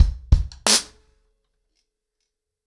ROOTS-90BPM.5.wav